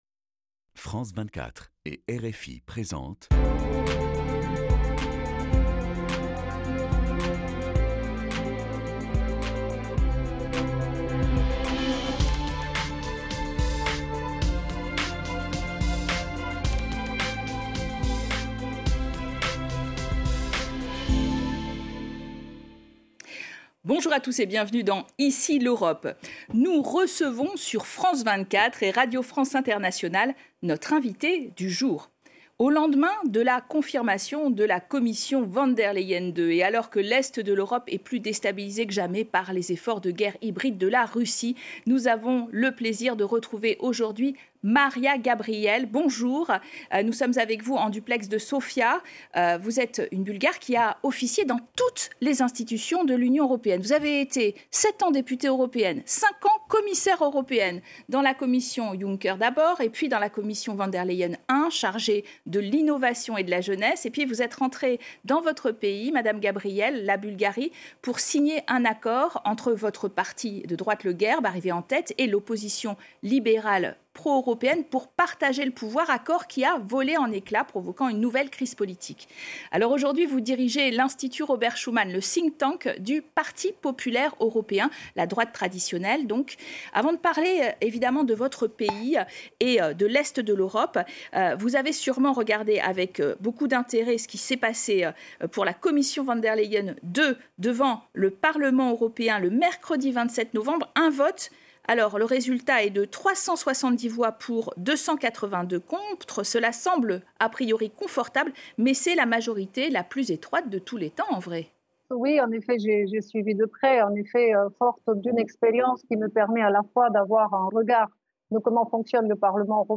Cette semaine, nous recevons Mariya Gabriel, ancienne commissaire européenne et ancienne ministre bulgare des Affaires étrangères.